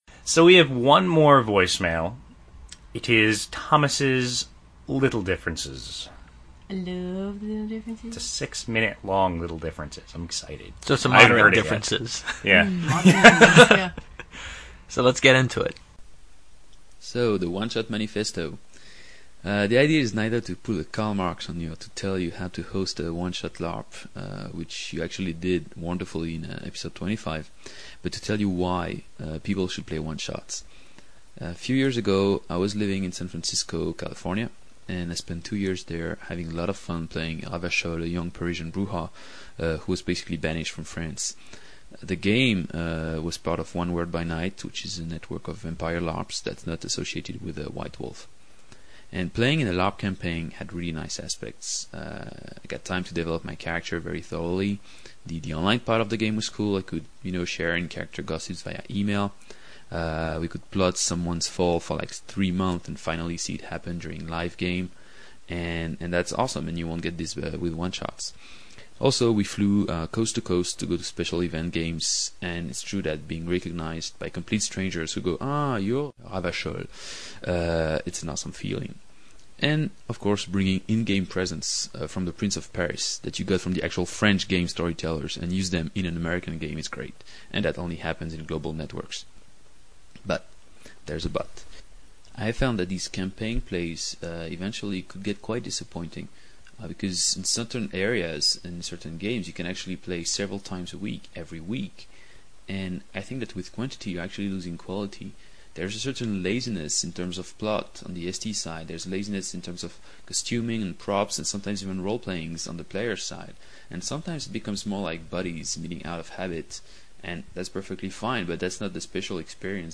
The idea was to add a european perspective to this US-centric podcast via voice messages.